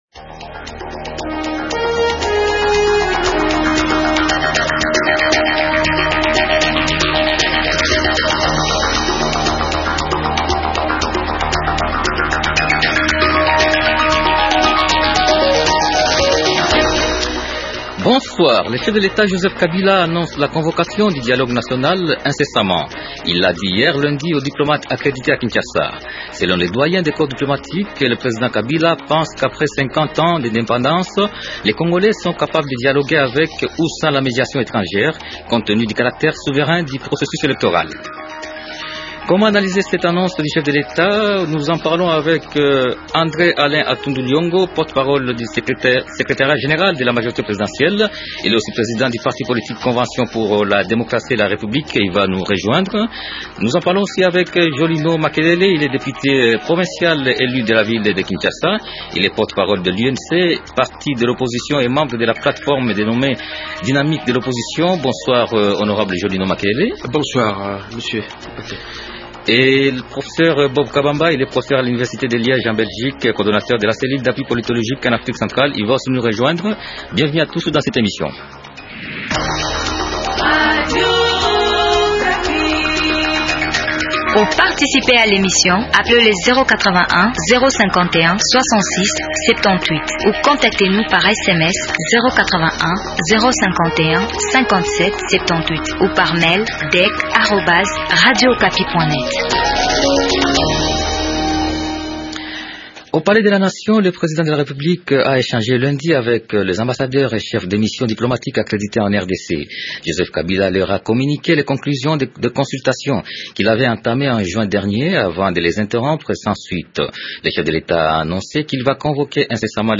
Les débatteurs de ce soir sont :